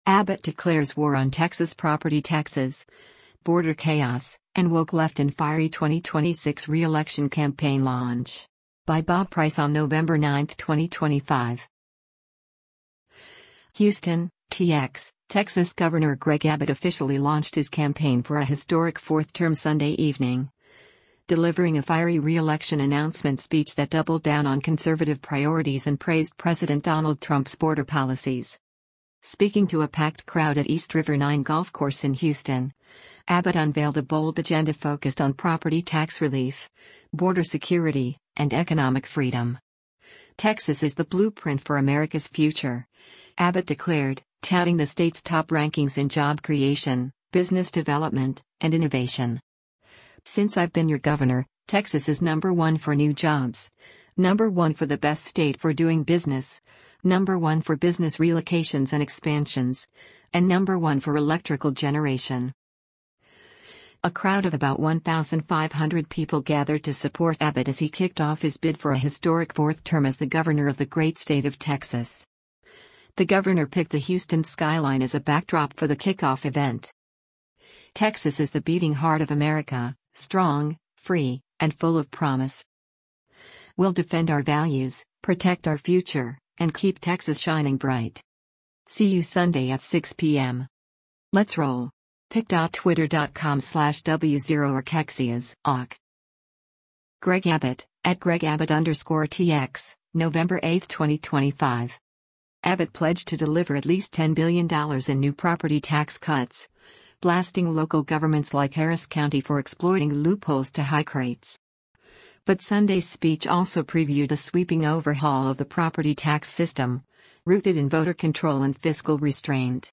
HOUSTON, TX — Texas Governor Greg Abbott officially launched his campaign for a historic fourth term Sunday evening, delivering a fiery re-election announcement speech that doubled down on conservative priorities and praised President Donald Trump’s border policies.
A crowd of about 1,500 people gathered to support Abbott as he kicked off his bid for a historic fourth term as the governor of the Great State of Texas.